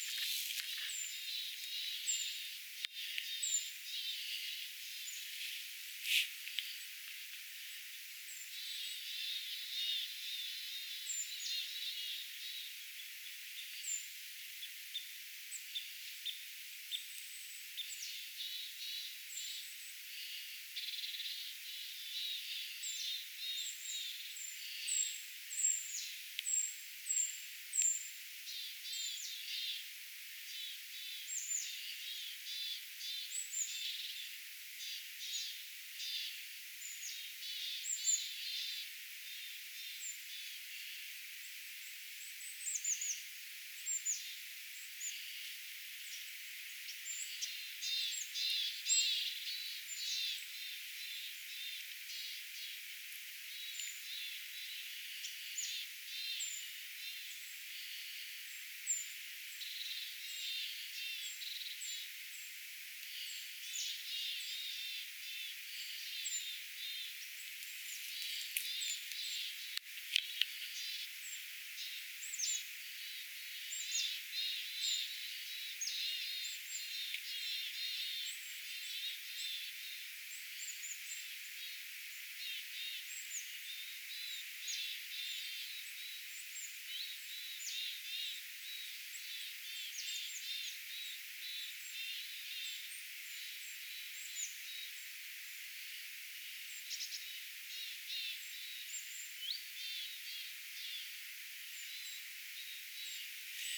tiaisparvi, ti-puukiipijä, isokäpylintu
tiaisparvi_ti-puukiipija_isokapylintu.mp3